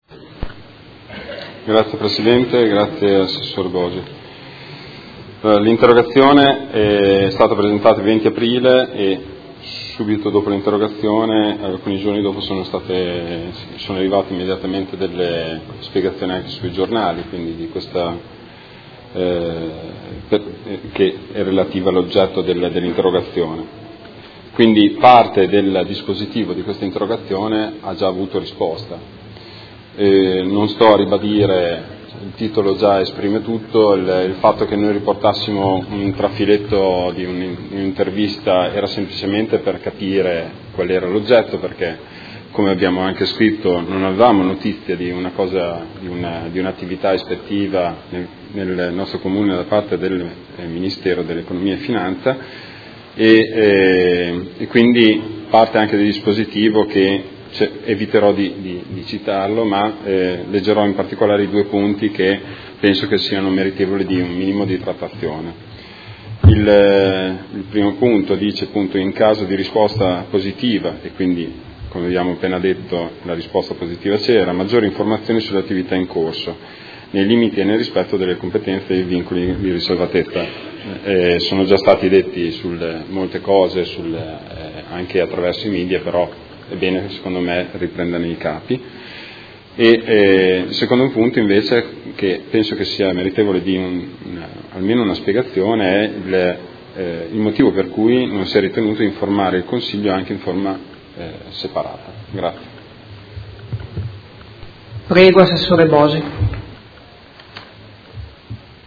Seduta del 17/05/2018. Interrogazione del Gruppo M5S avente per oggetto: Visita ispettiva MEF presso il Comune di Modena